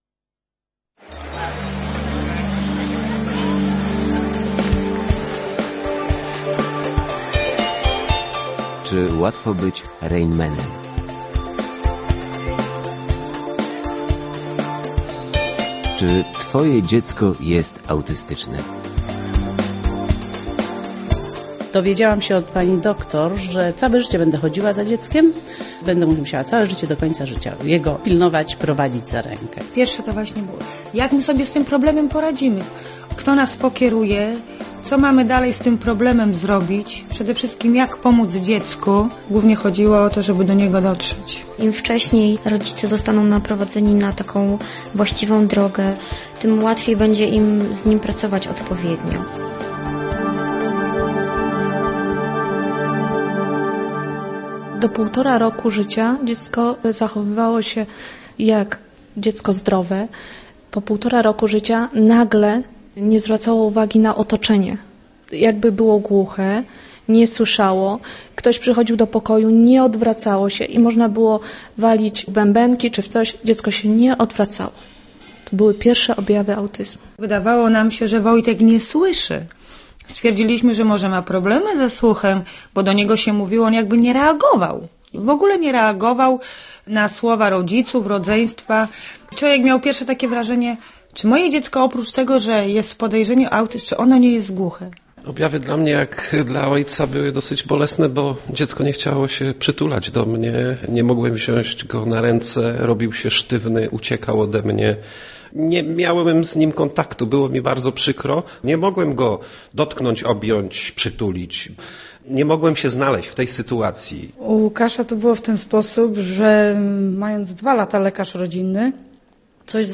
Cykl audycji radiowych "Czy łatwo być Rainmanem?" zrealizowaliśmy dzięki dofinansowaniu przez Państwowy Fundusz Rehabilitacji Osób Niepełnosprawnych w Warszawie w ramach programu "Pion".
W nagraniach udział wzięli terapeuci oraz rodzice dzieci autystycznych ? przedstawiciele naszego stowarzyszenia. Audycje uzupełniają wypowiedzi specjalistów z Fundacji Synapsis.